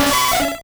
Cri de Nidorina dans Pokémon Rouge et Bleu.